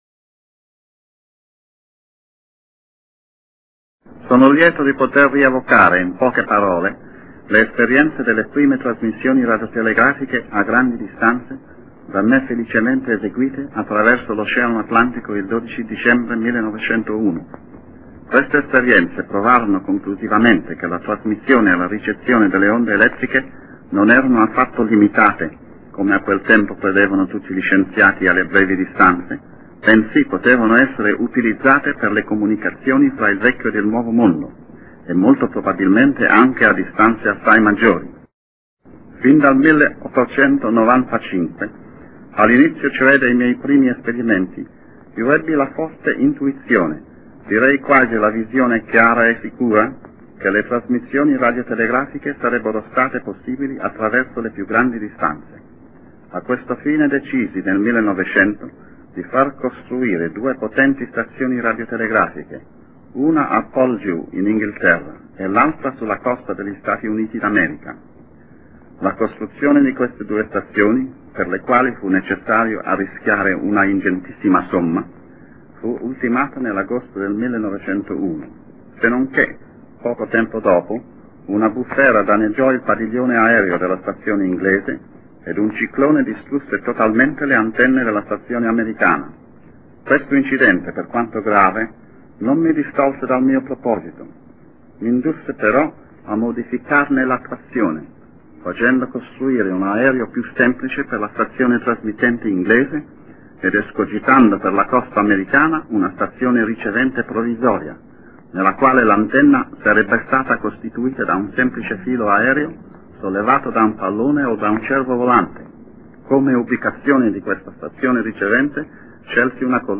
ASCOLTA LA VOCE DI GUGLIELMO MARCONI